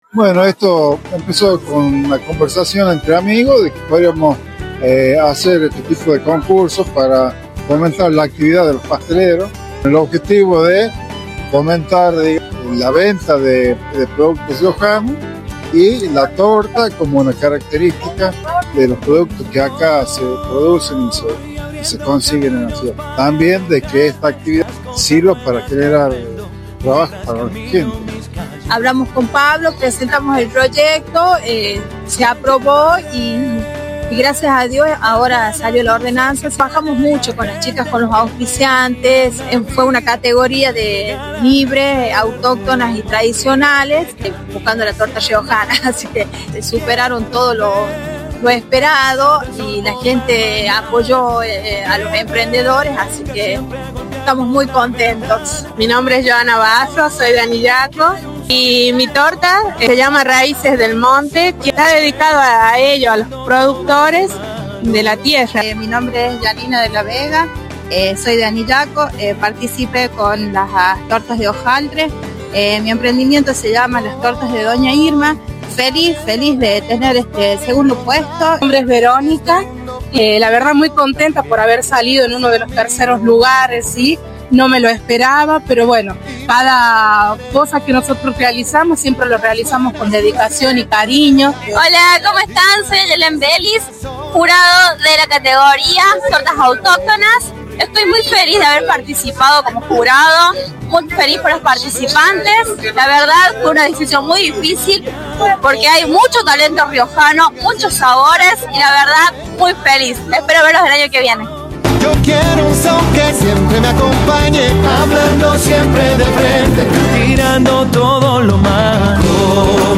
El Parque de la Ciudad fue escenario de una jornada que reunió talento, tradición y espíritu emprendedor con el Concurso de Emprendedores Buscando la Torta Riojana, donde pasteleras de distintos puntos de la provincia exhibieron su creatividad y dedicación.
La palabra del edil Pablo Herrera que acompaño a las y los emprendedores en esta hermosa iniciativa.